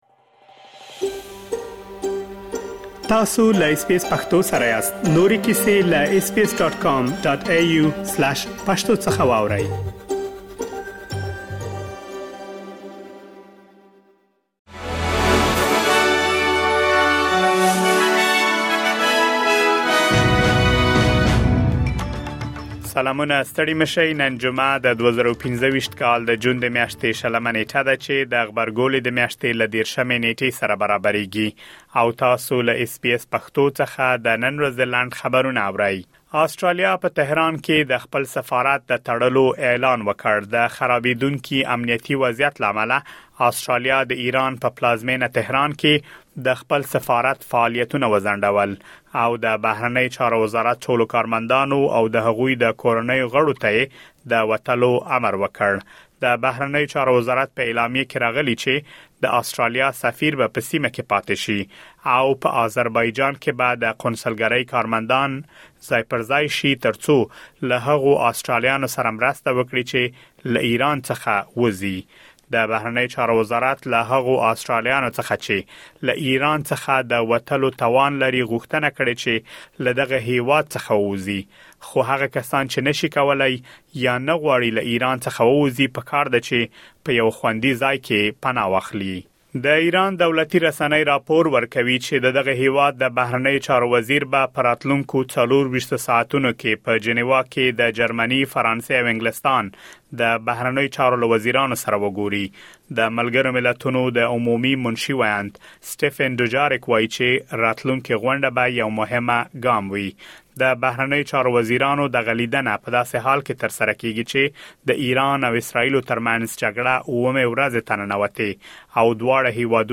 د اس بي اس پښتو د نن ورځې لنډ خبرونه | ۲۰ جون ۲۰۲۵